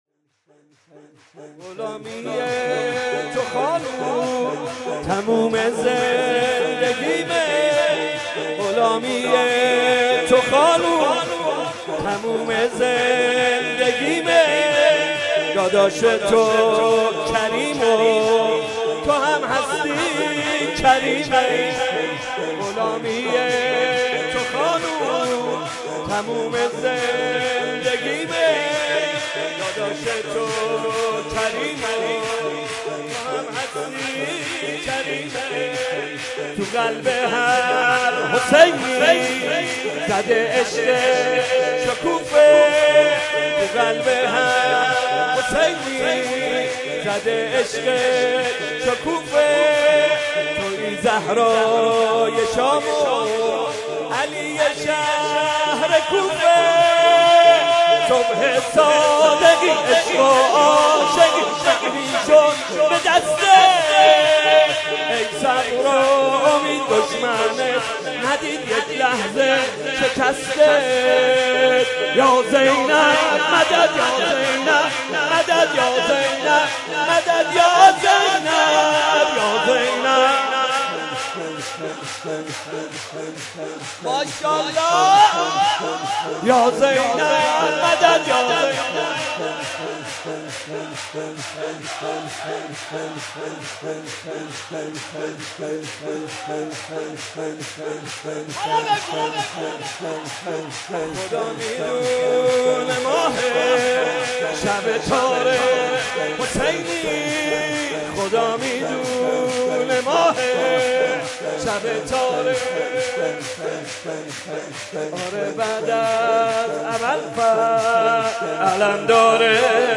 مولودی‌خوانی «محمدرضا طاهری» در وصف حضرت زینب(س) صوت - تسنیم
صوت مولودی خوانی «محمد‌رضا طاهری» در مدح زینب کبری(س) را با هم می‌شنویم.